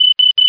alarm1.mp3